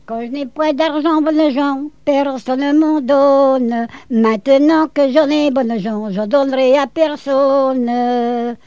danse : branle
collecte en Vendée
Pièce musicale inédite